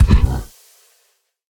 Minecraft Version Minecraft Version snapshot Latest Release | Latest Snapshot snapshot / assets / minecraft / sounds / mob / camel / dash4.ogg Compare With Compare With Latest Release | Latest Snapshot
dash4.ogg